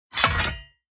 UI_Putdown_Bronze.ogg